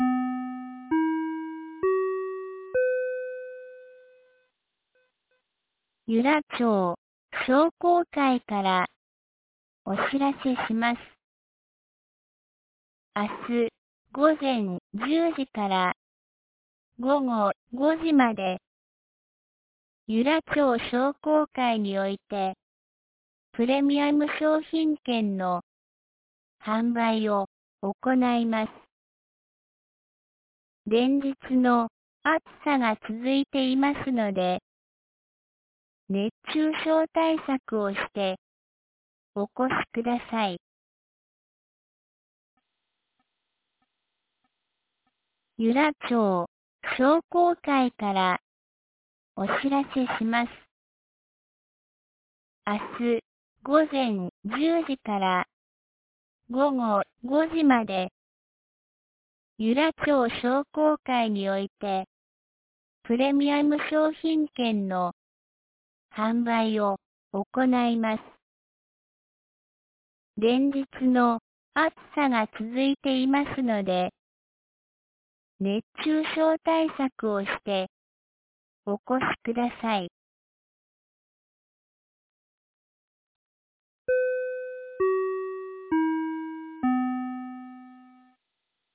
2025年08月30日 17時11分に、由良町から全地区へ放送がありました。